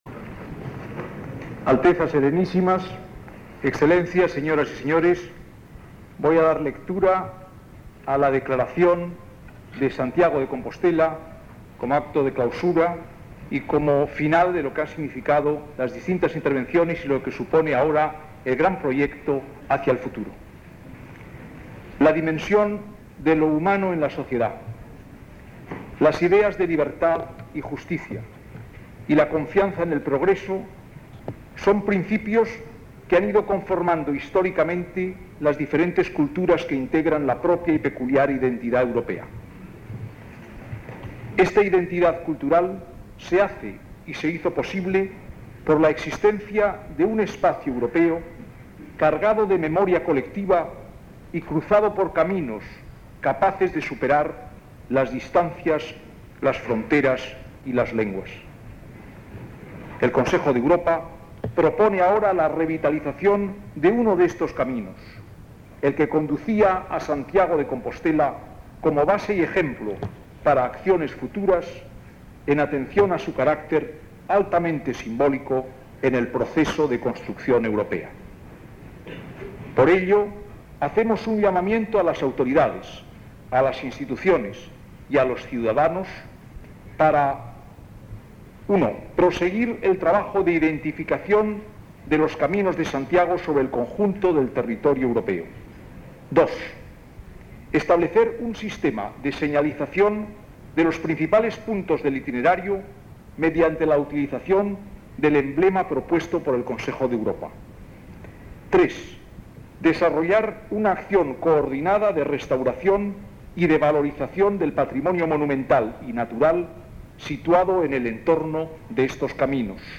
Lectura de D. Marcelino Oreja, Secretario General del Consejo de Europa, de la Declaración de Santiago de Compostela como Itinerario Cultural Europeo
Acto de proclamación del Camino de Santiago como Itinerario Cultural Europeo. 1987